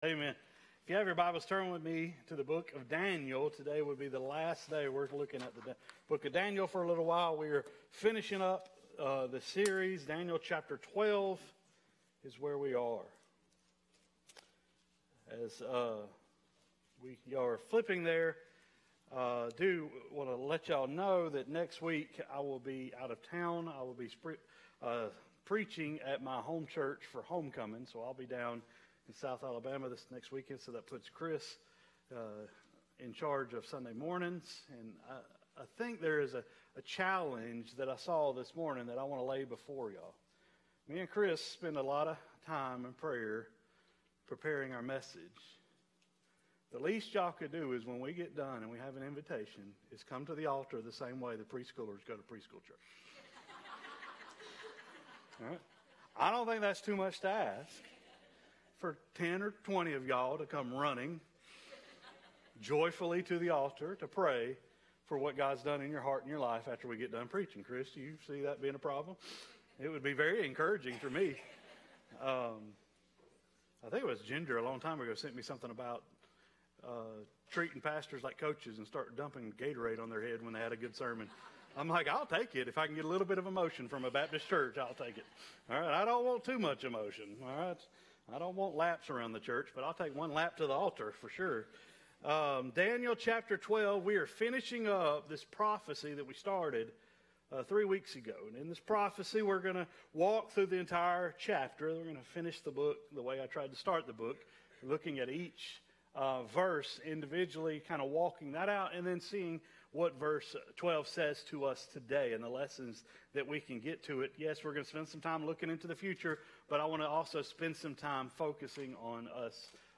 Sermons | Piney Grove Baptist Church